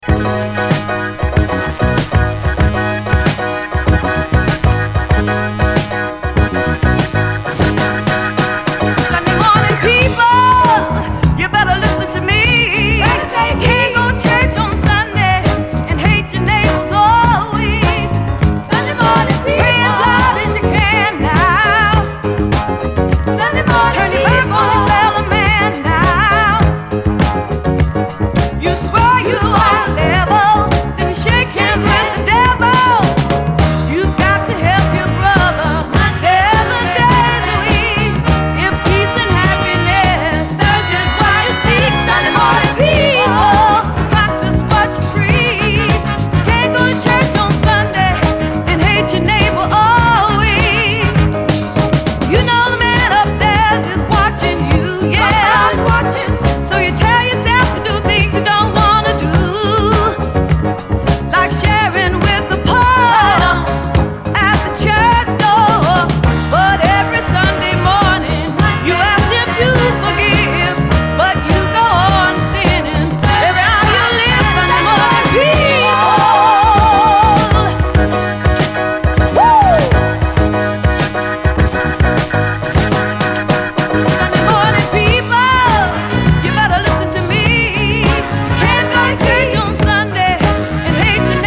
SOUL, 70's～ SOUL, 7INCH
イントロのファズ・ギターのループが有名！